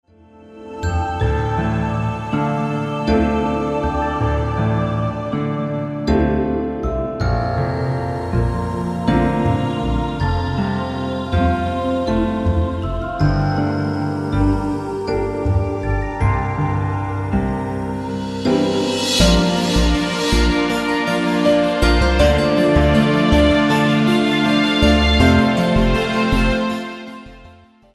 delikatny niczym sen, klimat odprężenia